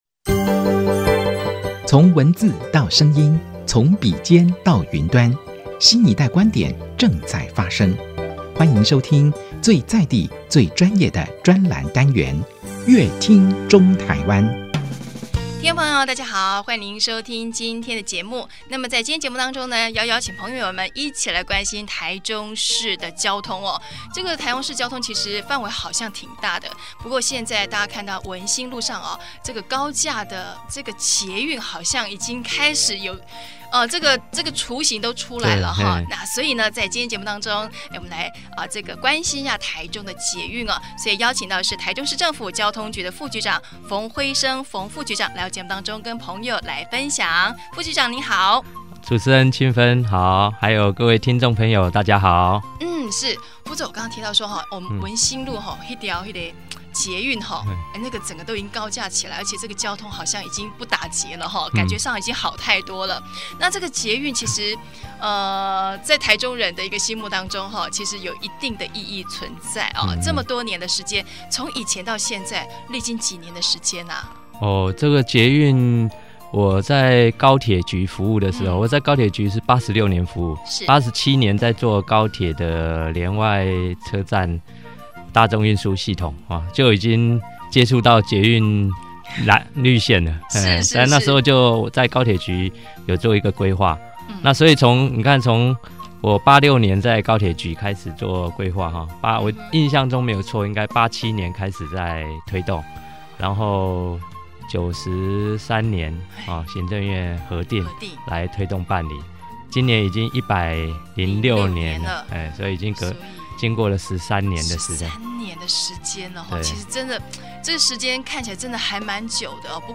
本集來賓：台中市政府交通局馮輝昇副局長 本集主題：捷運綠線 2018年試營運 本集內容： 歷經13年的台中捷運